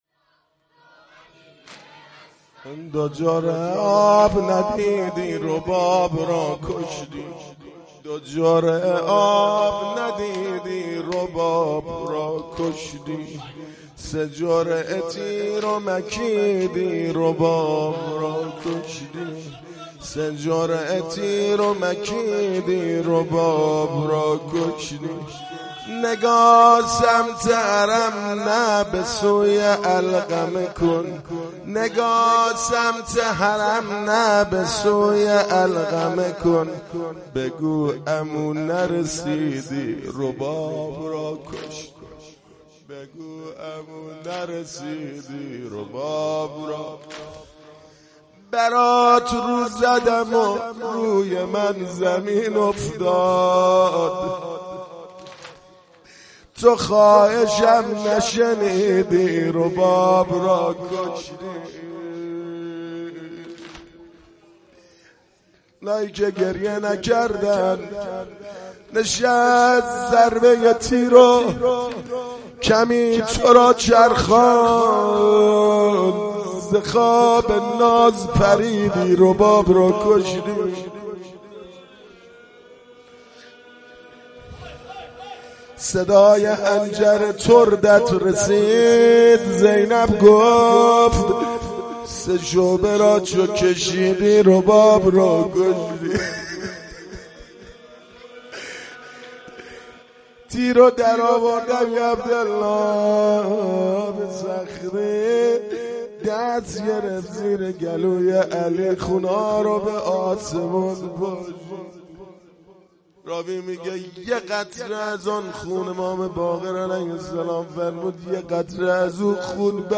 مداحی شب هفتم محرم 98 ( واحد دوم )